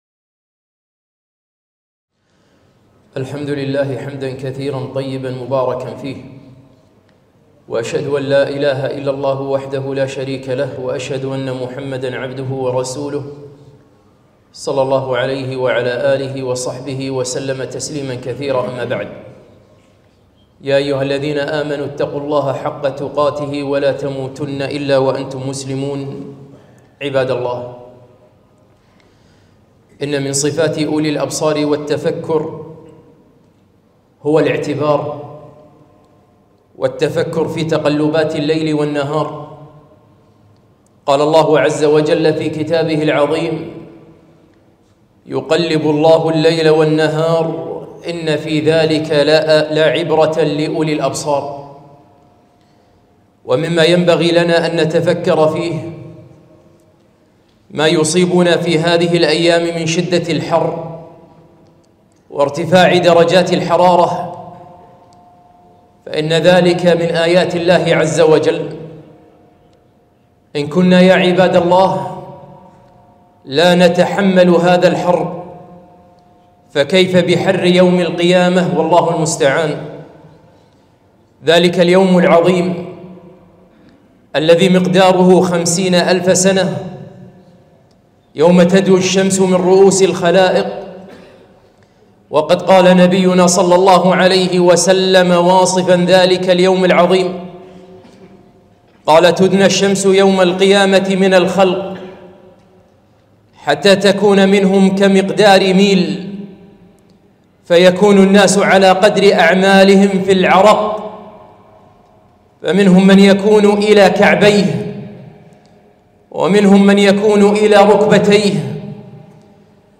خطبة - اتعظوا من شدة الحر